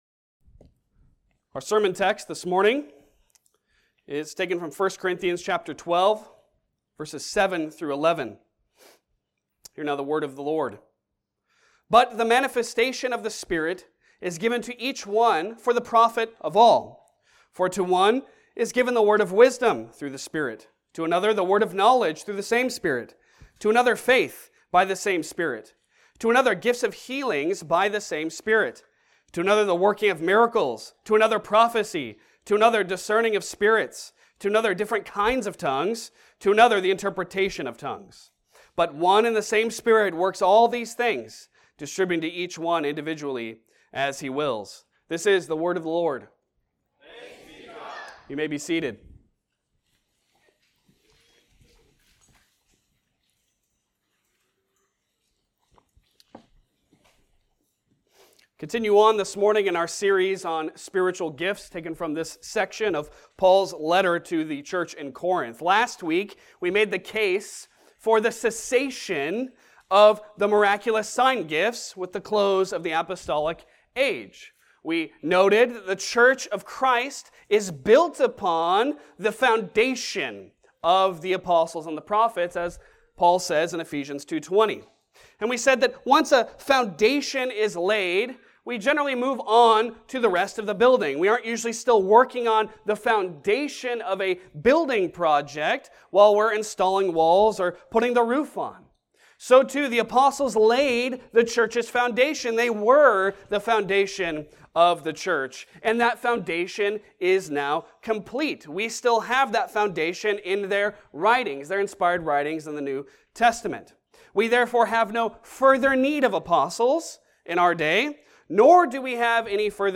Passage: 1 Corinthians 12:7-11 Service Type: Sunday Sermon